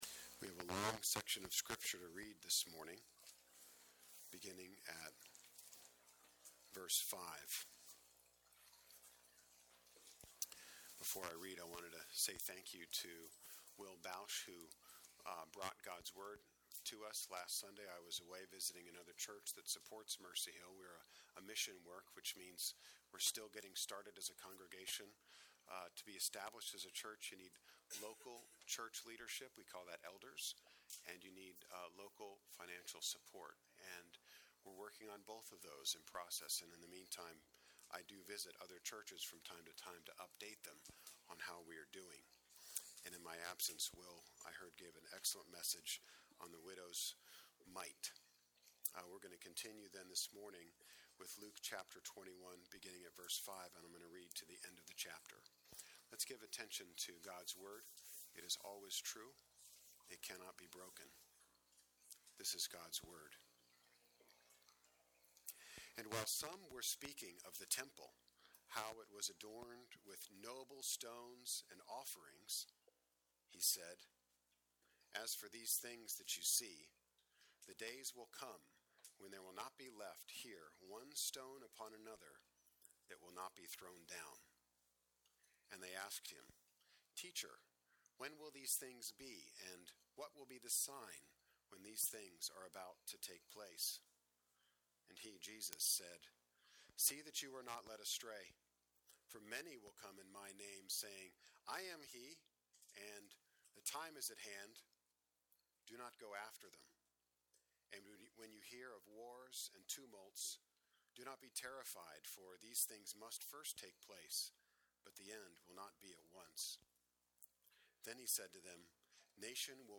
Jesus is the Center of Time - Mercy Hill Presbyterian Sermons - Mercy Hill NJ